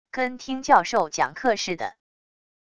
跟听教授讲课似的wav音频